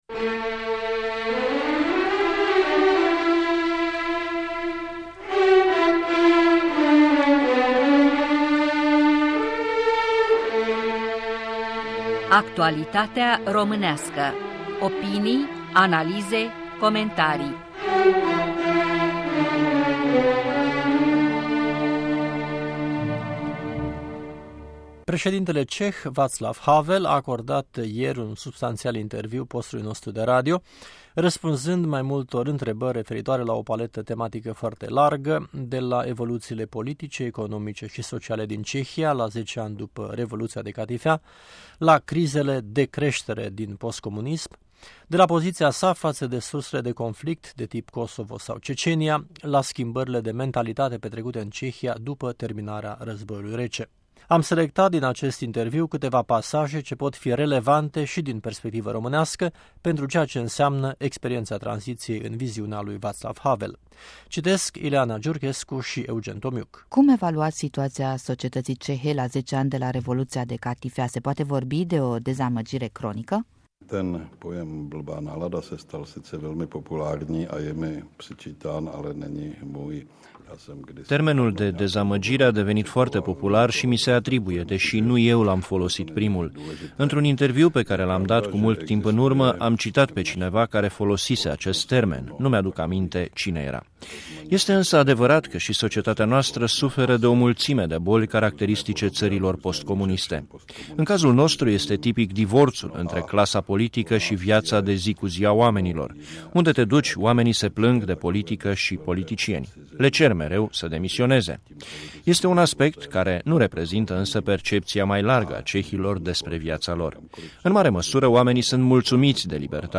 Interviul Radio Europa Liberă cu Vaclav Havel